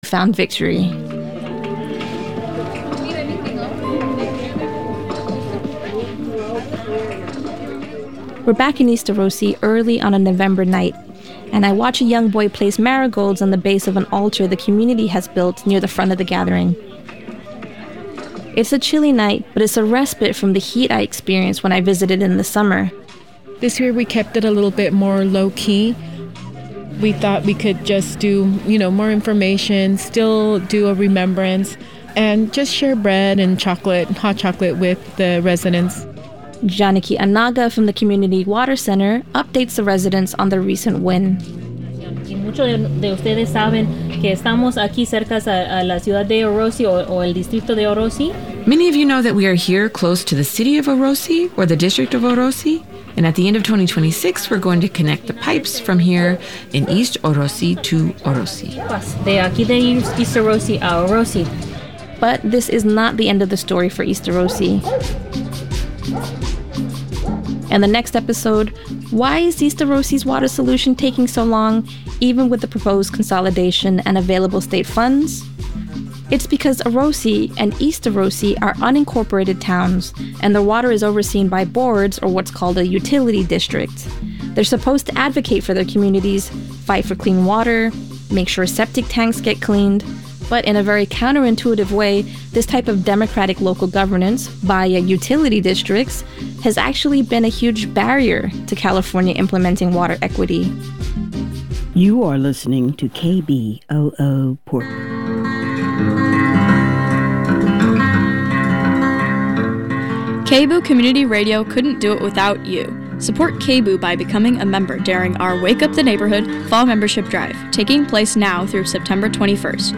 Interview with Lucy Sante